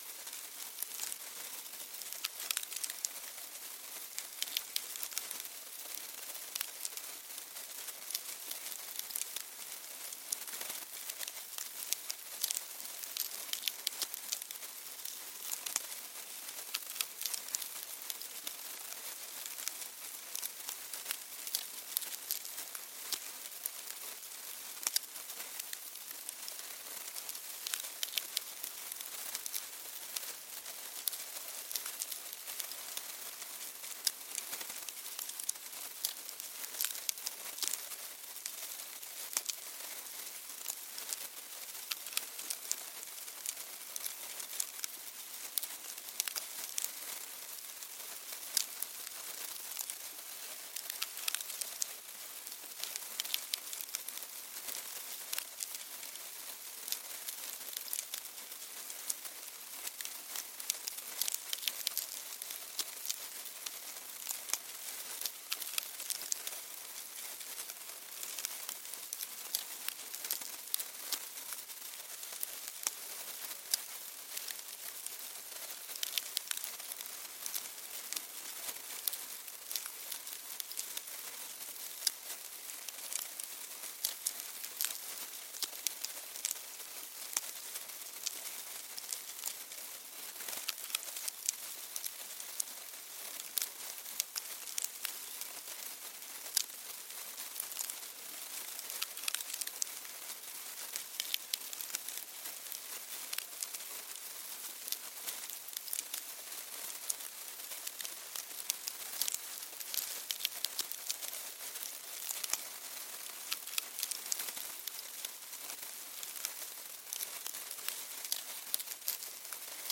Звуки термитов
Гигантские особи термитов